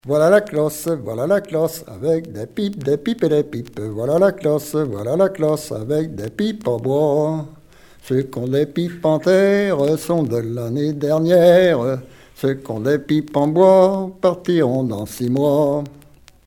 Chants brefs - Conscription
Pièce musicale éditée